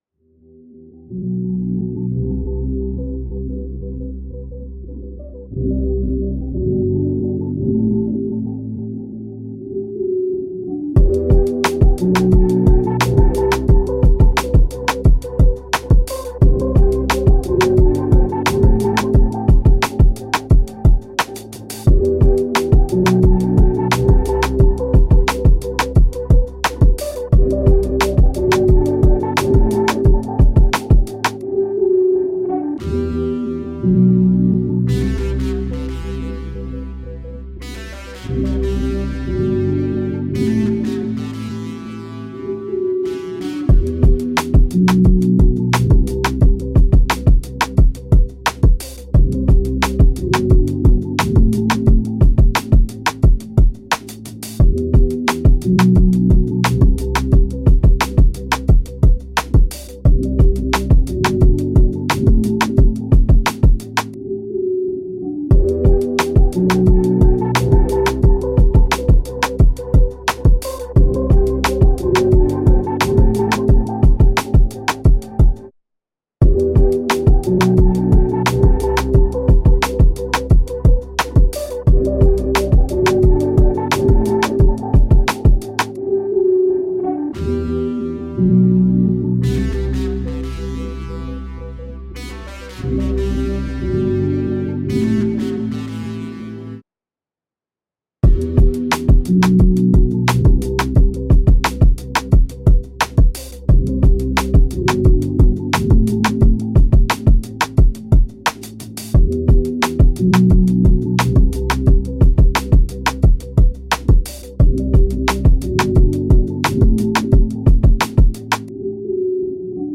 Hip Hop, R&B
G Major